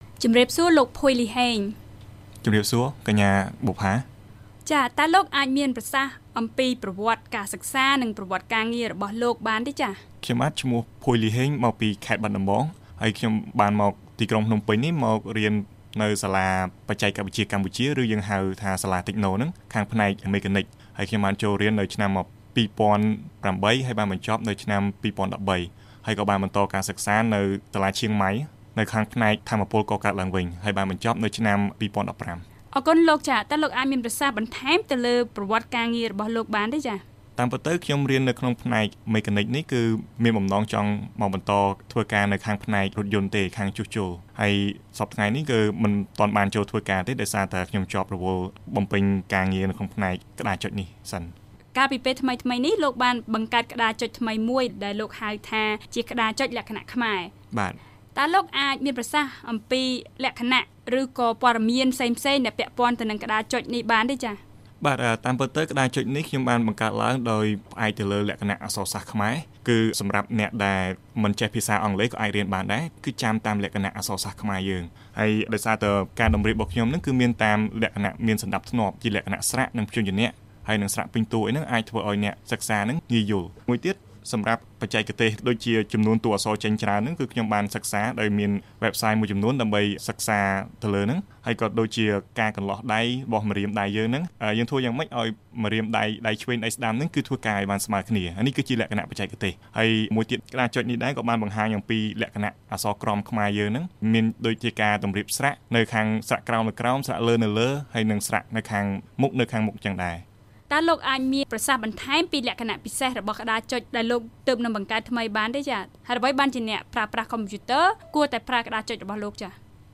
បទសម្ភាសន៍ VOA៖ ក្តារចុចលក្ខណៈខ្មែរជួយវាយតួអក្សរខ្មែរបានលឿន